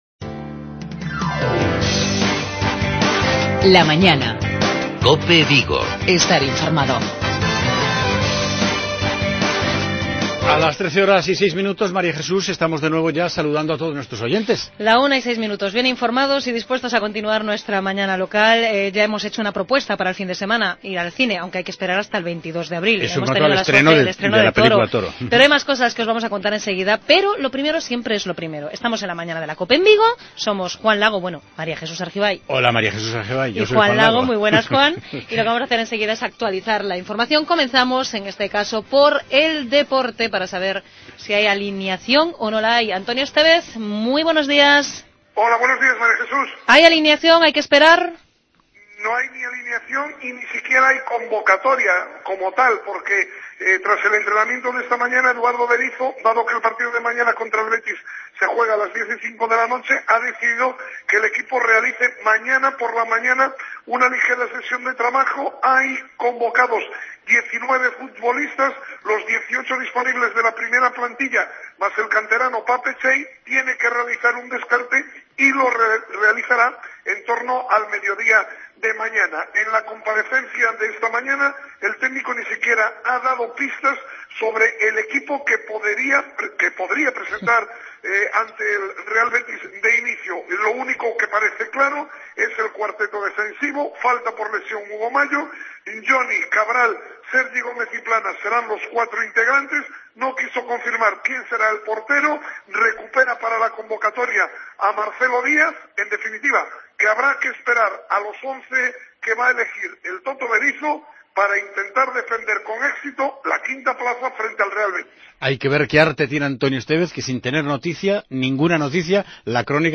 AUDIO: Cerramos esta semana de radio en COPE Vigo con la segunda parte de nuestro magazine local, en clave cultural.